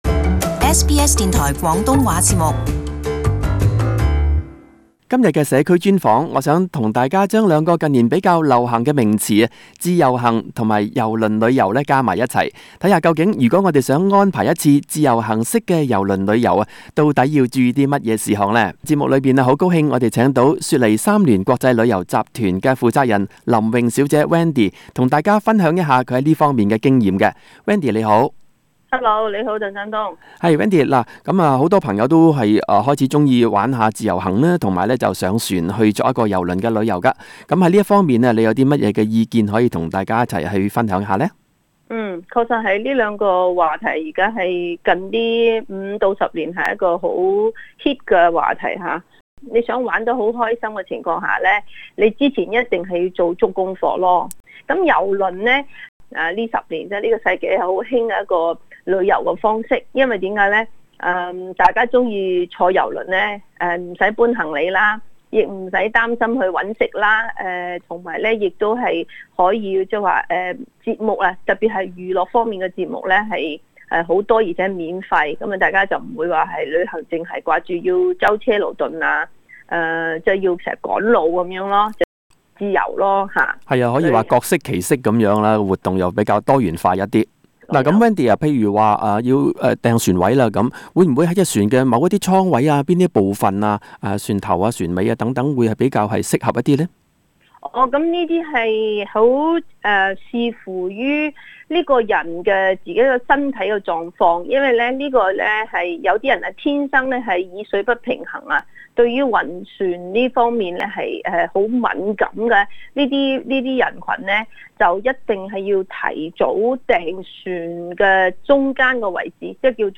【社區專訪】自助遊輪假期精讀貼士